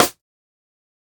Percs
BattleCatRim.wav